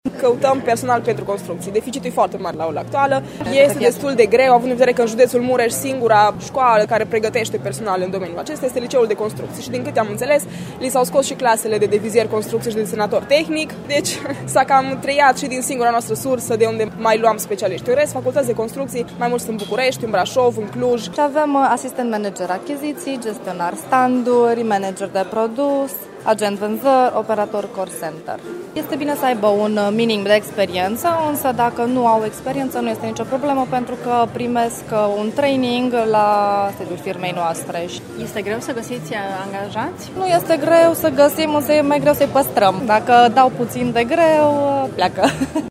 Unele firme de IT au chiar 50 de posturi disponibile, însă alți angajatori se plâng că găsesc greu oameni cu pregătire :